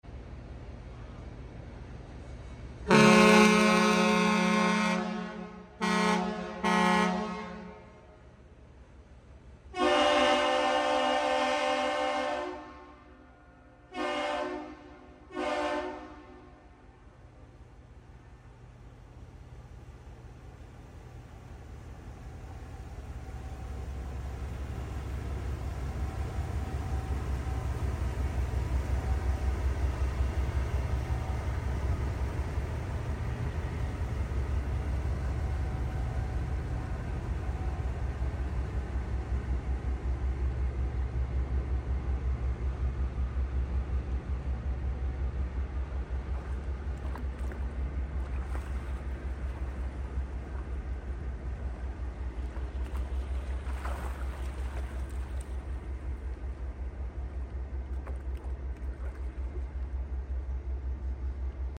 Historic 98 year old tugboat sound effects free download
Historic 98 year old tugboat Missouri wakes up Duluth, exchanging a LOUD 2:30AM salute with the Aerial Lift Bridge. The 88' long Missouri was built in 1927 at American Shipbuilding, in Lorain, Ohio.